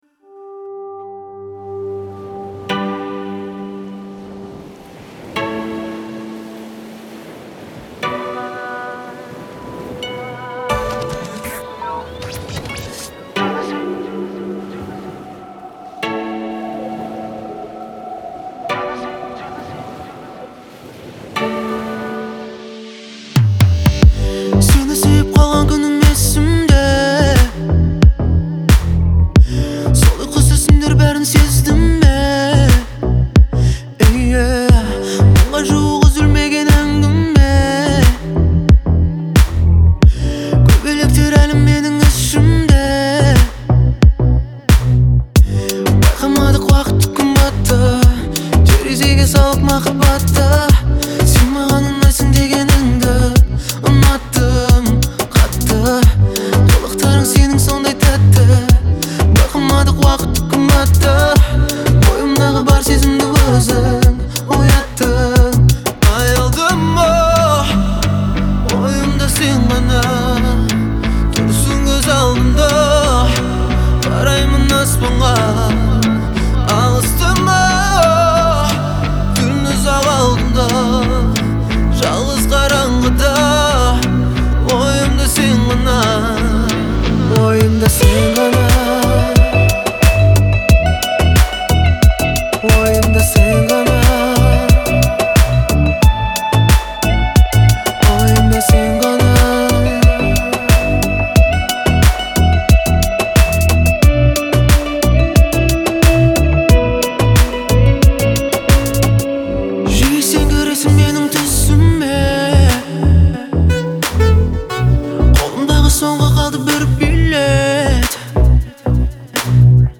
выполненный в жанре поп с элементами этнической музыки.